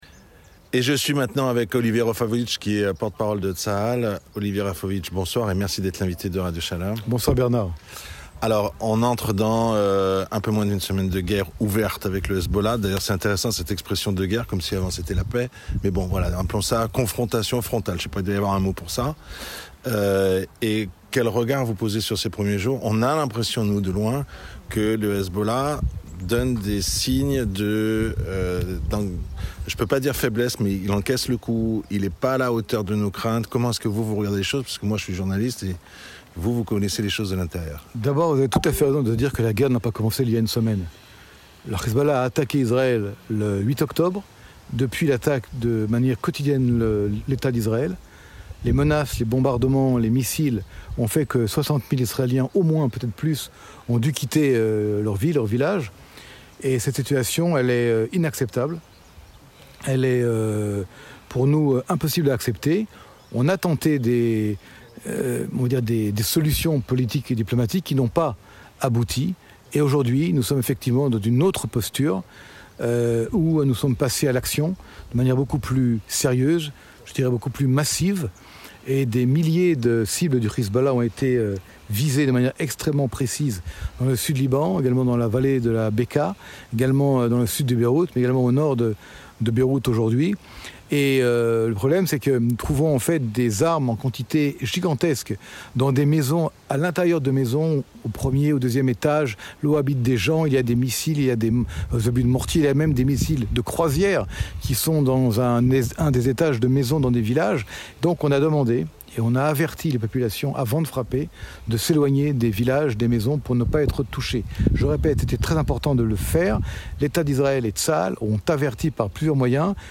Olivier Rafowicz : interview au milieu des ruines de Nir Oz